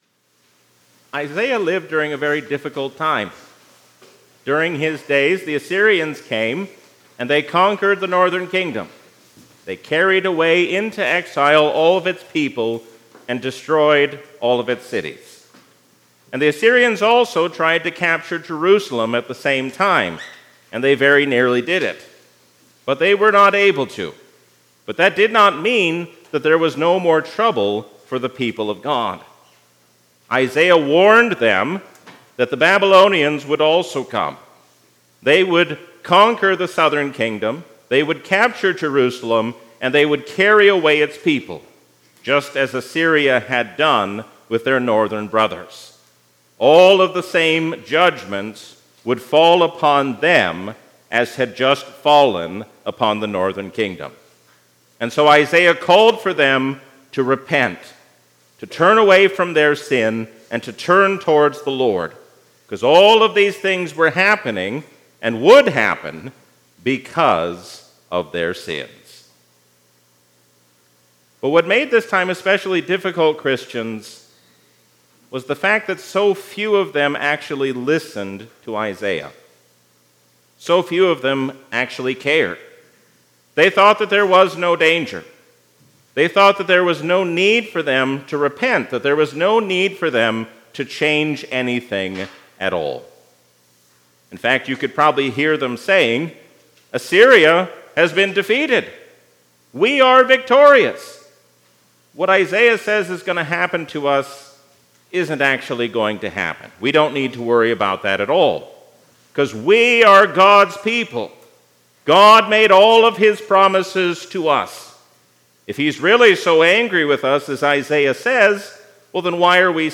A sermon from the season "Lent 2024."